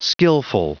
Prononciation du mot skillful en anglais (fichier audio)
Prononciation du mot : skillful